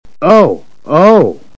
Man, this voice actor really wasn't into it